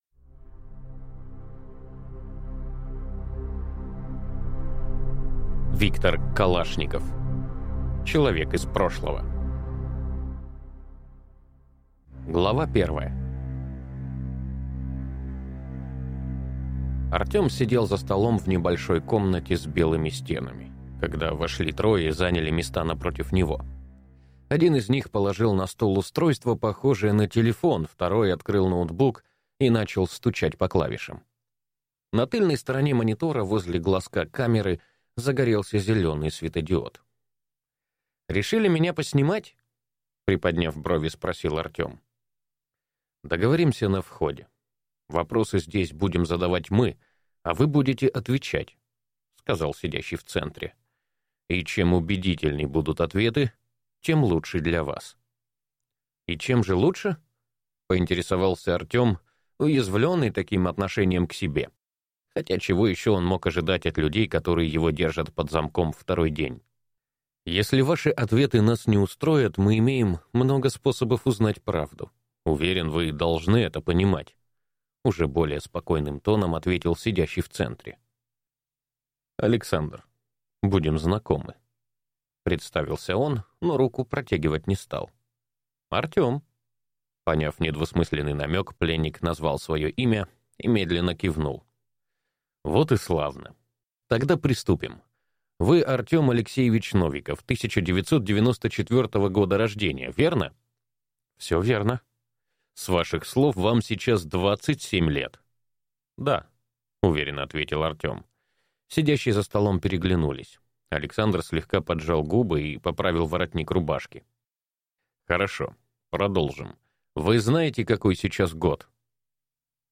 Аудиокнига Человек из прошлого | Библиотека аудиокниг